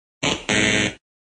Incorrect Meme Effect Sound sound effects free download